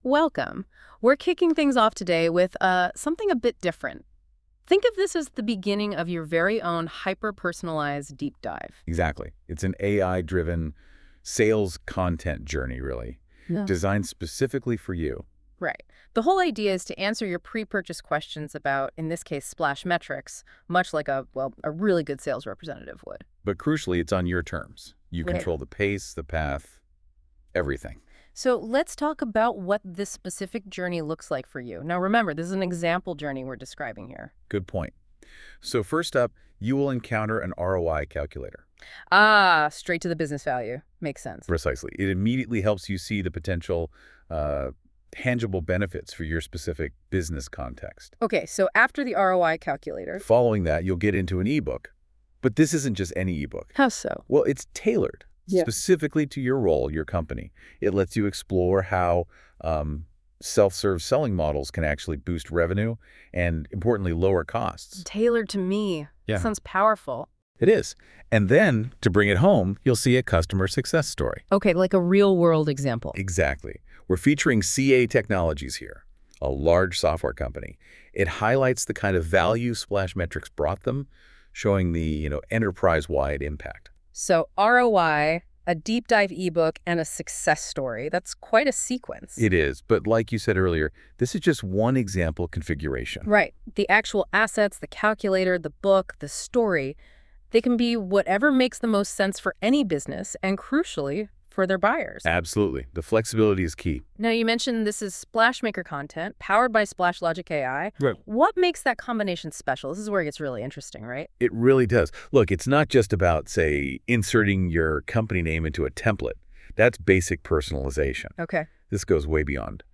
The Journey Teaser button above provides an AI-generated conversational overview of all the journey content so you’ll know exactly what you’re getting into.